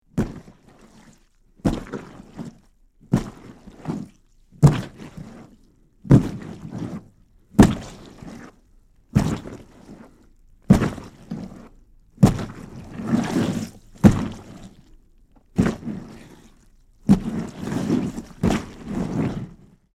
Alien Walking; Large Dull Thud, Wet Footsteps And Movement.